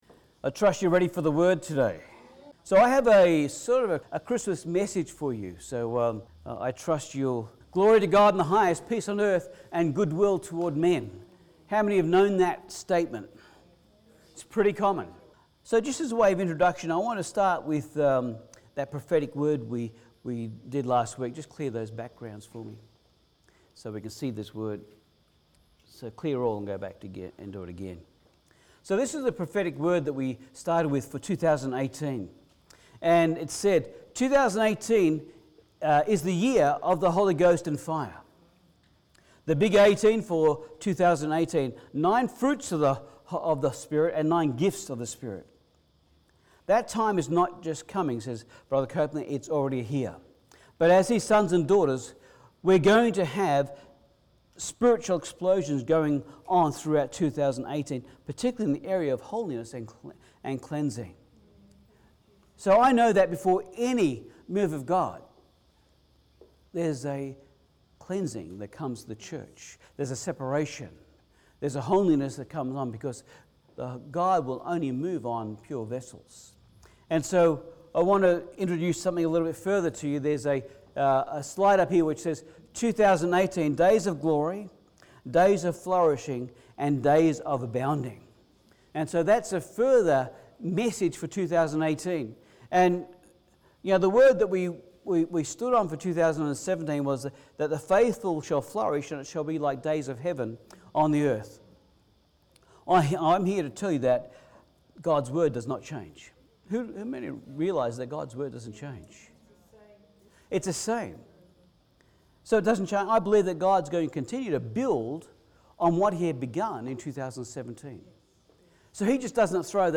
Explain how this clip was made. Service Type: Sunday Service Christmas-Service-24-Dec-17.mp3